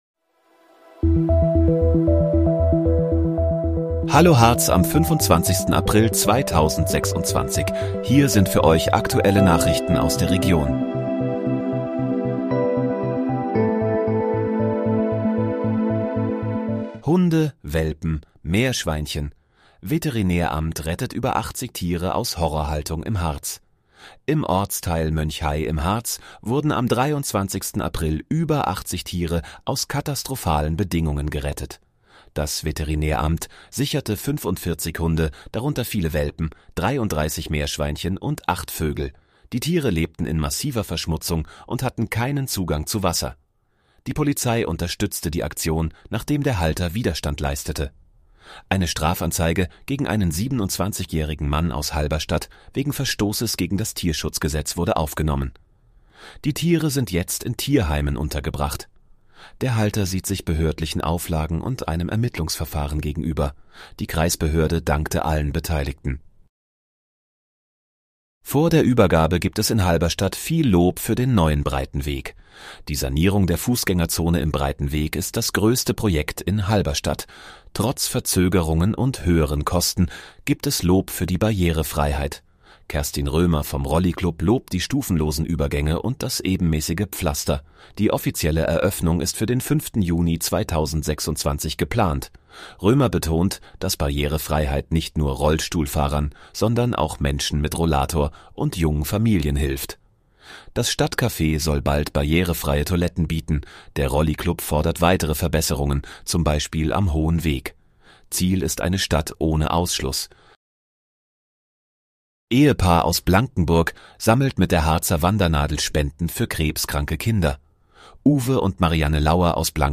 Hallo, Harz: Aktuelle Nachrichten vom 25.04.2026, erstellt mit KI-Unterstützung